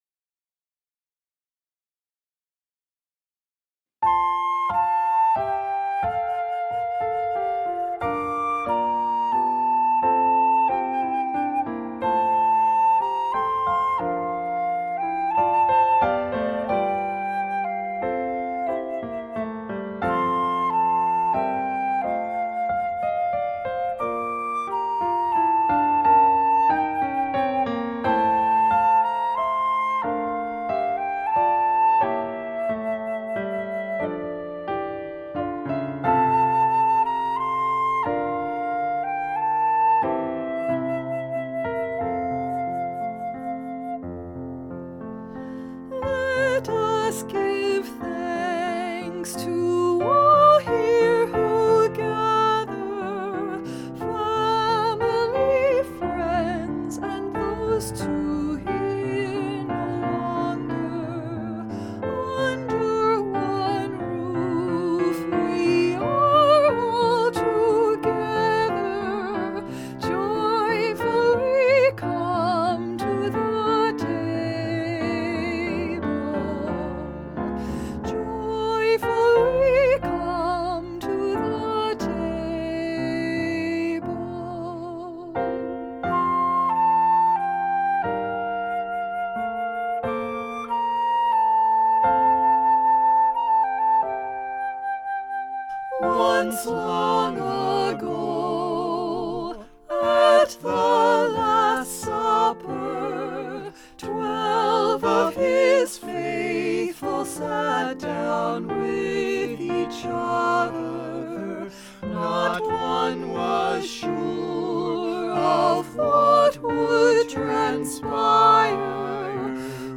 Here he offers a reflection on composing sacred music; viewing it as both spiritual practice and gift.
I was so moved by the beautiful pastoral quality of the music that I wanted to try and write in that style.